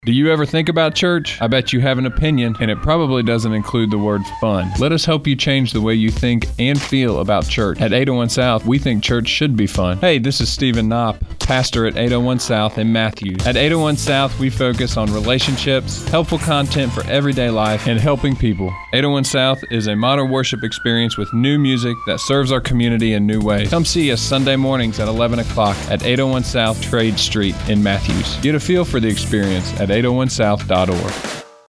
Radio Spot for Channel 96.1: